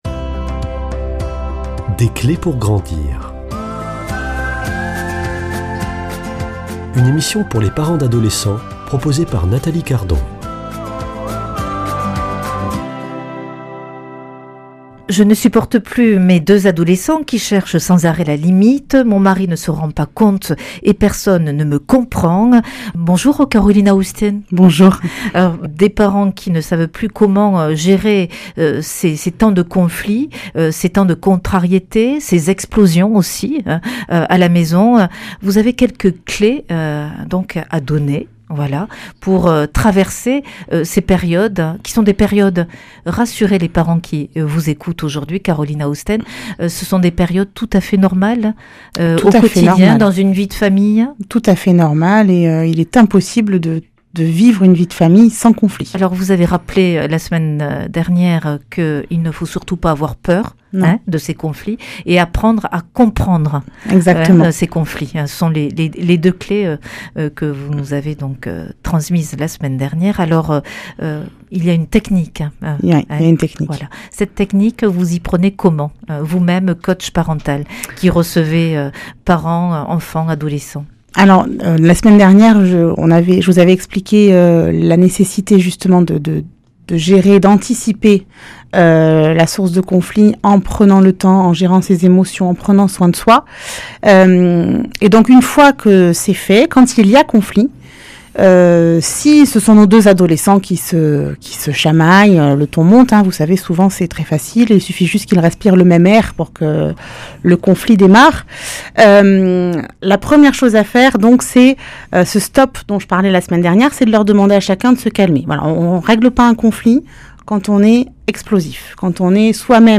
[ Rediffusion ] Soutien, confiance, responsabilité et communication sont quelques recettes indispensables pour guider, valoriser et construire un (…)